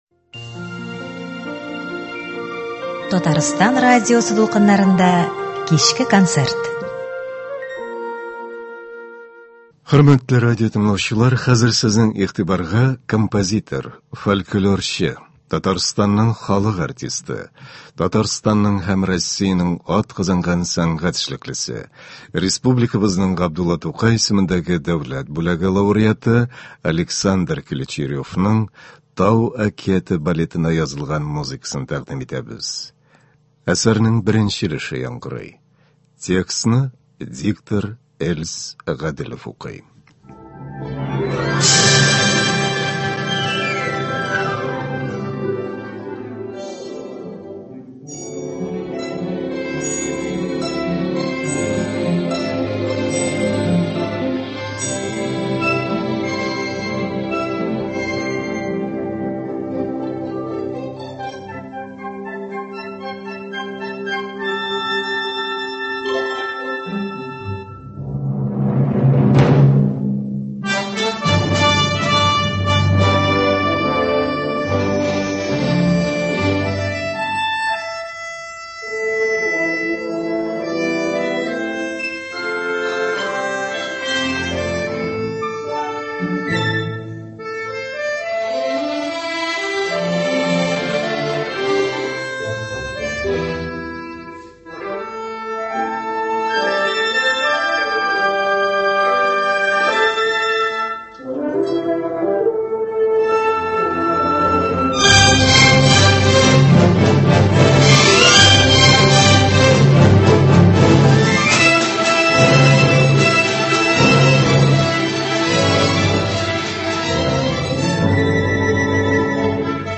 балетыннан фрагментлар.